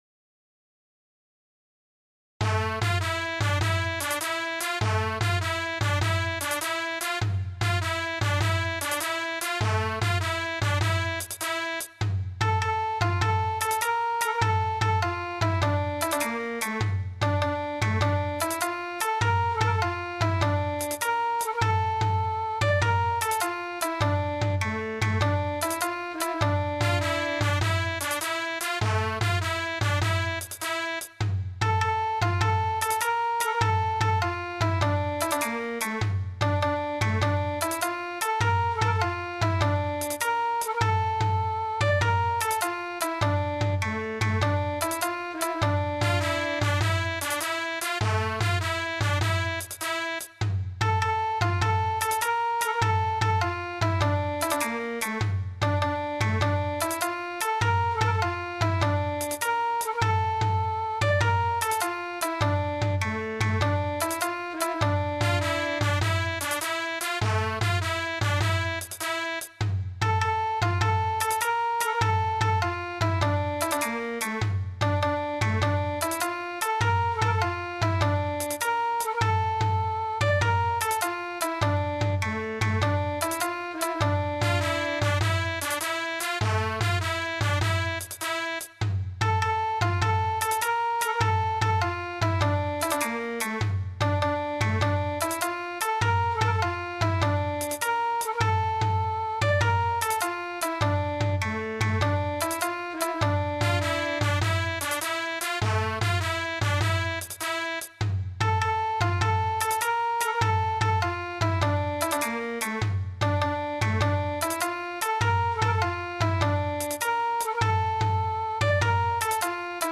（歌なしのみ）